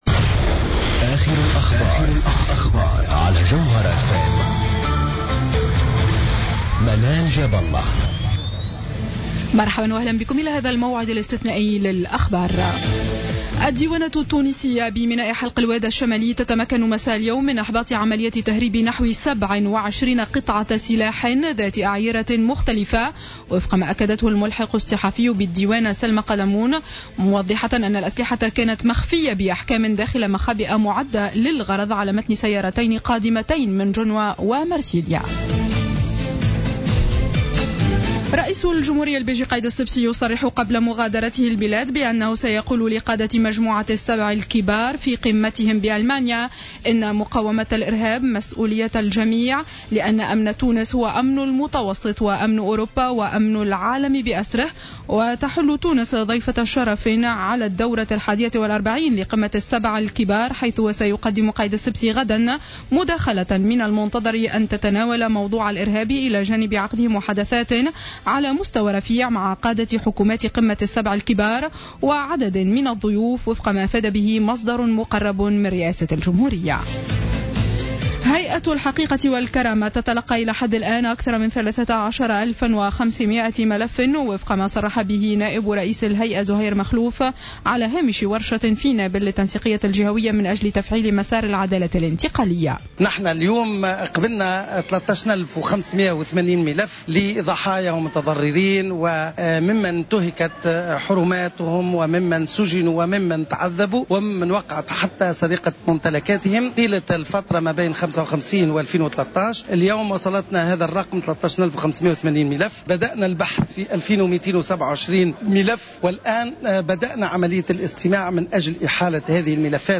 نشرة أخبار السابعة مساء ليوم الأحد 07 جوان 2015